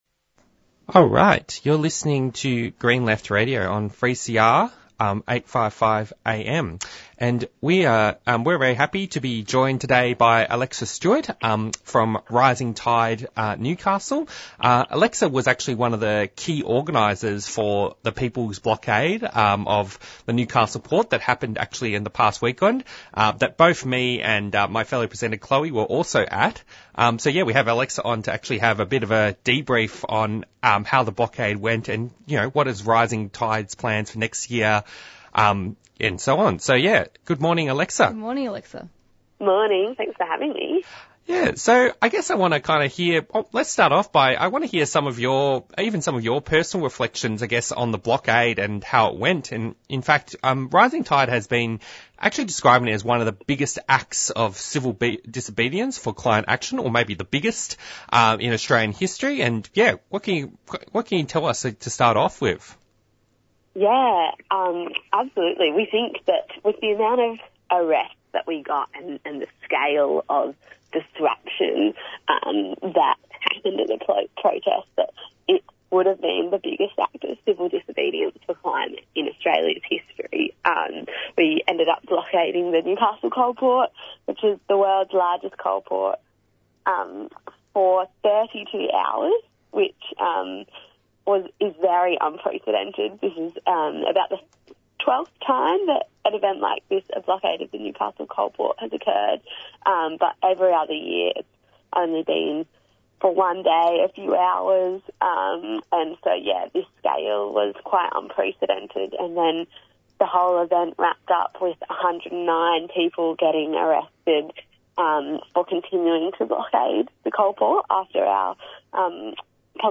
Presenters discuss the latest headline news.